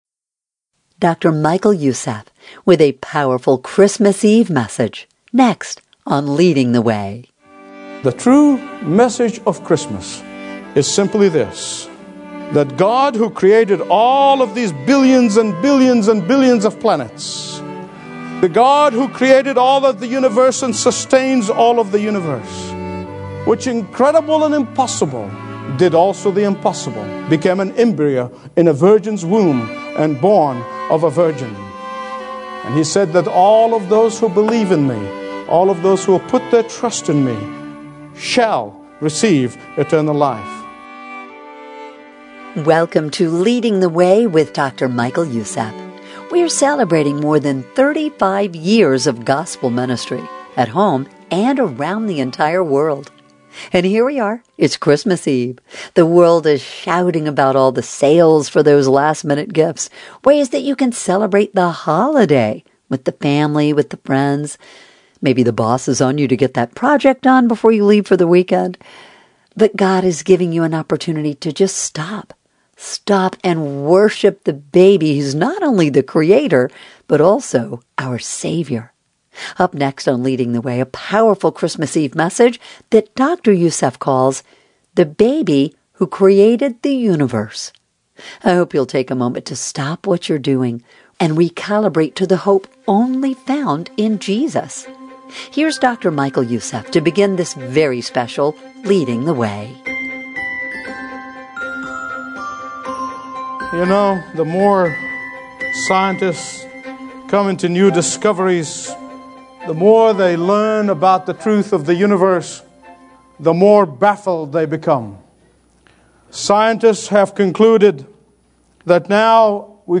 In this powerful service, discover why Jesus is not merely a moral teacher, but God revealed in the flesh—the only Savior who lived without sin, died for our rebellion, and offers eternal life to all who repent and believe.